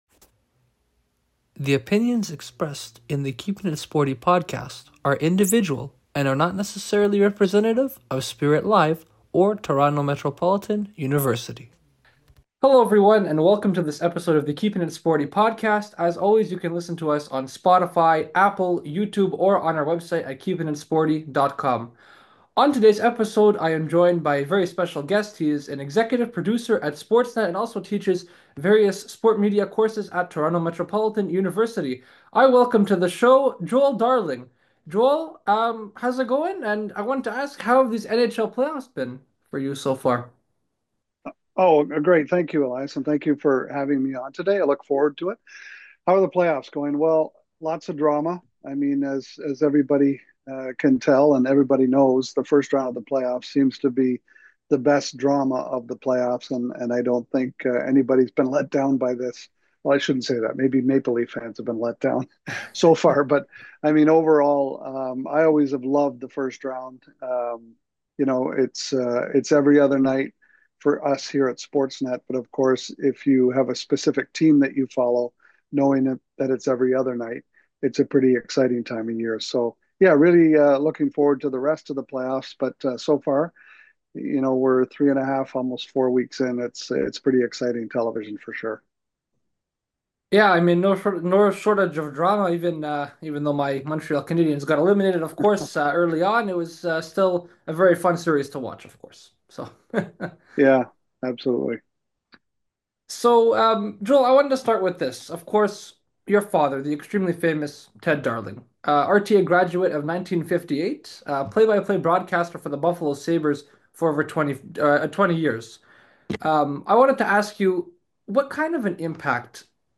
This podcast is based around discussing the challenges and hardships in sports. A variety of sports teams will also be discussed.